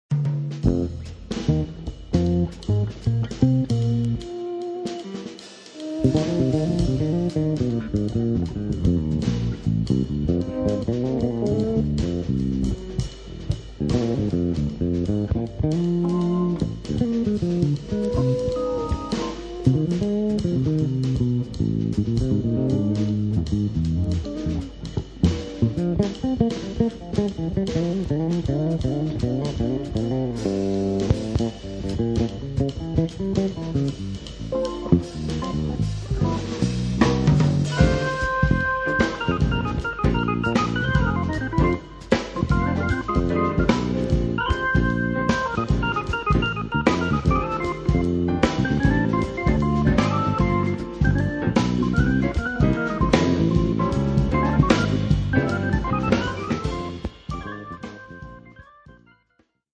dalla consueta energia dei fiati